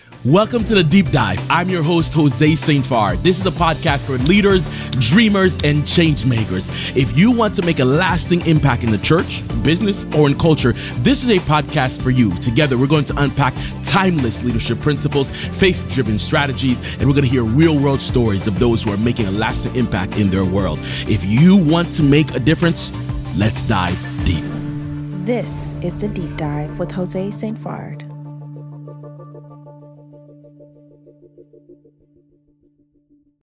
We break down the “no weeks off” rule, why growth often plateaus after early momentum, and how to create content that serves the person scrolling instead of making it about you. If you’re a leader or faith leader building trust online, this conversation will reset your approach.